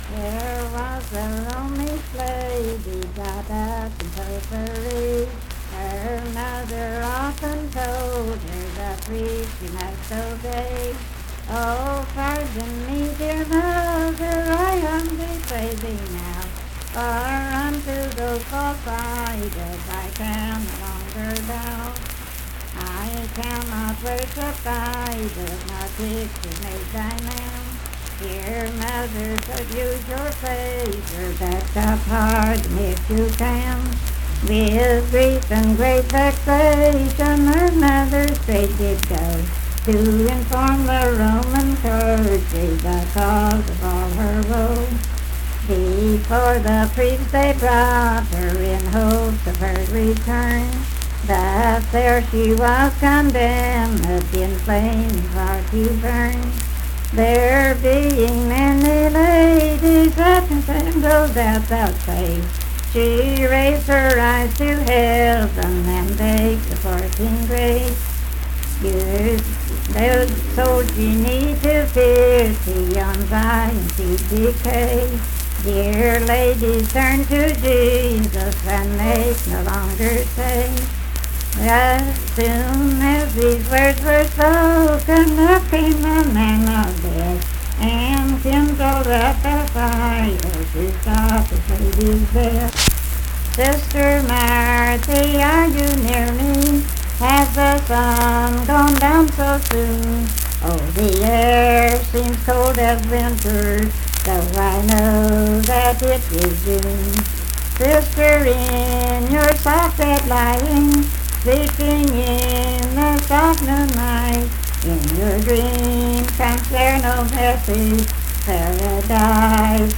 Unaccompanied vocal music performance
Verse-refrain 3 (4).
Voice (sung)